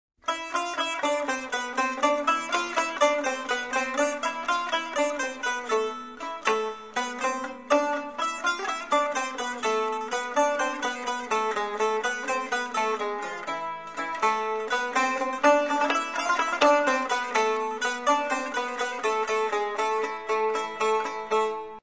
土耳其民謠１
專輯中一位聲音渾厚的男性演唱土耳其歌謠，演唱者以自然發聲法演唱，運用胸腔共鳴，唱長音時震動喉嚨，略帶抖音，以聲音展現豐富的感情，非常具有民俗風特色，但其所唱的歌詞內容我們不甚了解，只能透過簡介臆測，有點像鴨子聽雷！
不過，對於專輯中絃鳴樂器與彈撥樂器及擊鼓節奏，我們可是大感興趣。